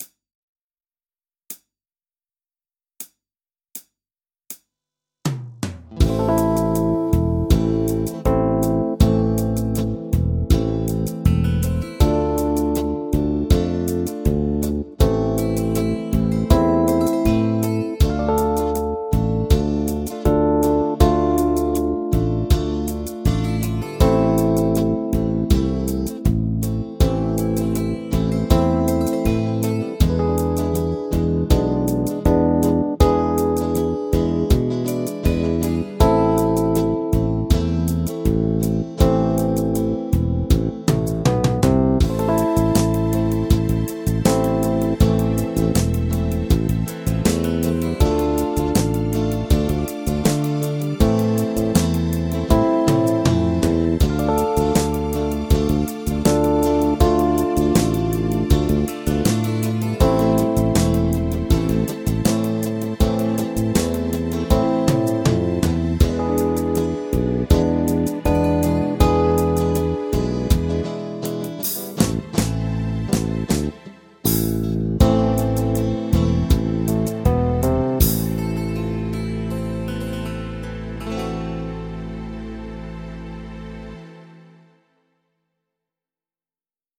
ヨナ抜きマイナー・スケール ギタースケールハンドブック -島村楽器